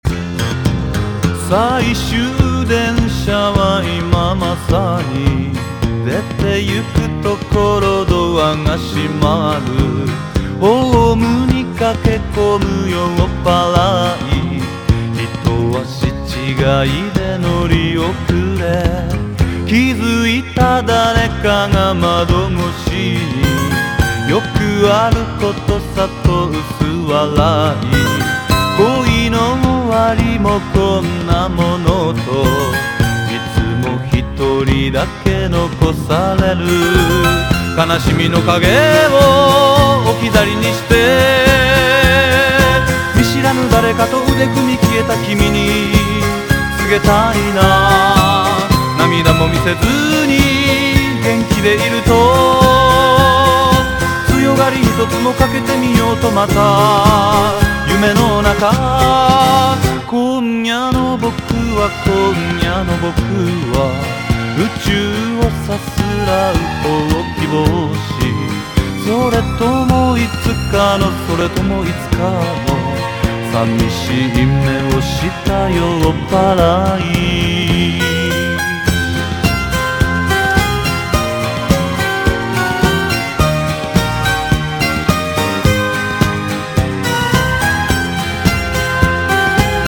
JAPANESE GROOVE / DRUM BREAK
スリリングなホーンと共に弾けるファンキーな演奏が最高です！